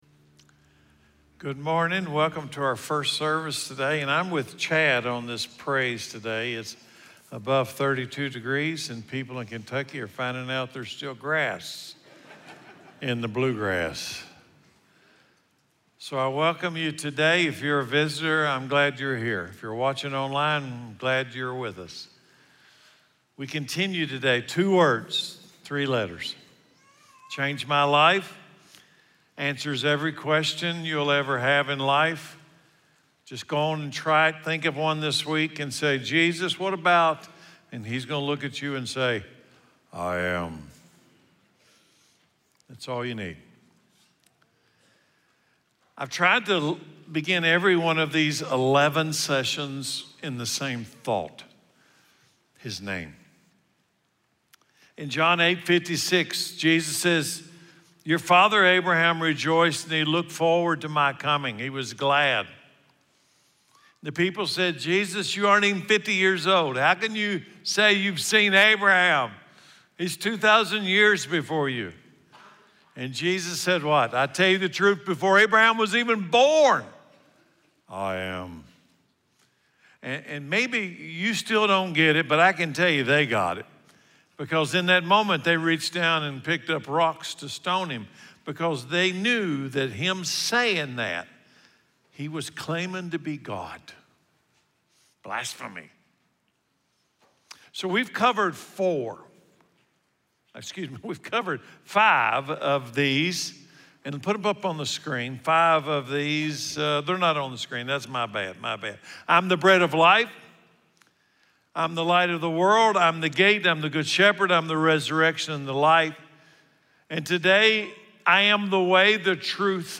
Ninevah Christian Church sermon for I Am Series. Jesus is the I AM: the way, truth, and life.